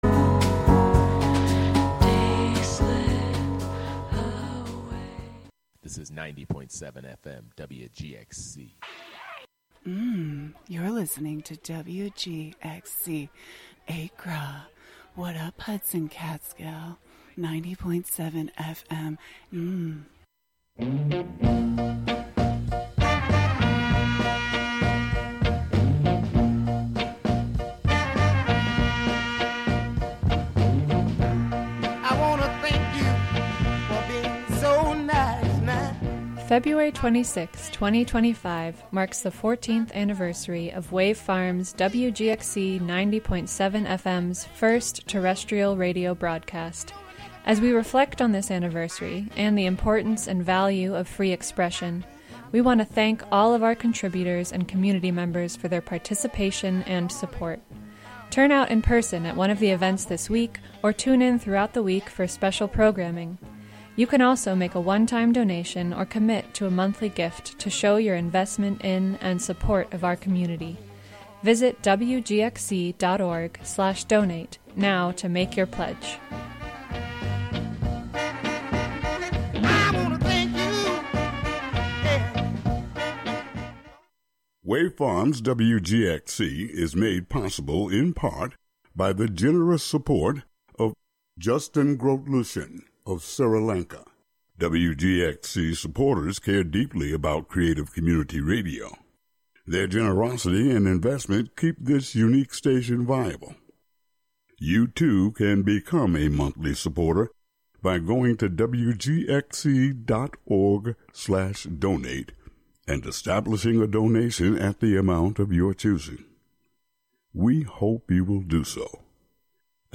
Live from the Catskill Maker Syndicate space on W. Bridge St. in Catskill, "Thingularity" is a monthly show about science, technology, fixing, making, hacking, and breaking with the amorphous collection of brains comprising the "Skill Syndicate."